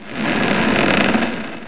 00051_Sound_creaky.mp3